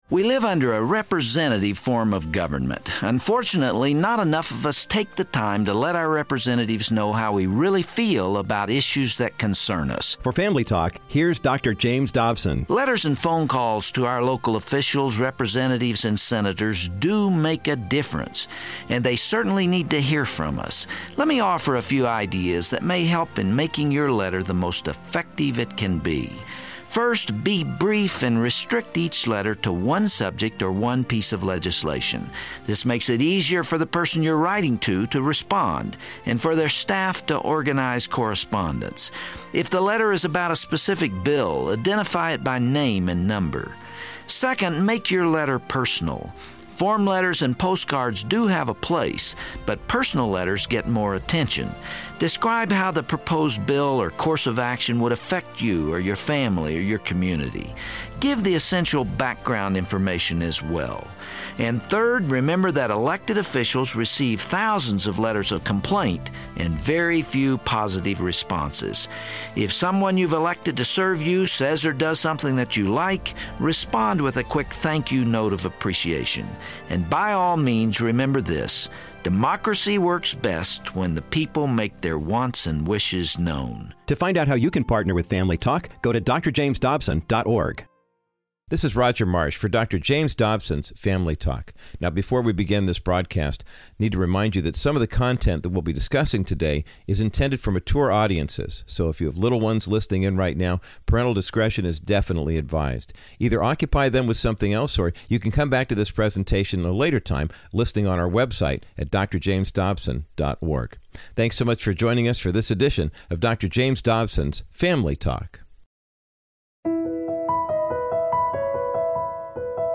Dr. Dobson addresses this humanitarian crisis through the reading of his July newsletter. He shares his heartbreaking visit to the holding facilities in Texas, and discredits the medias misconceptions about the refugees living conditions.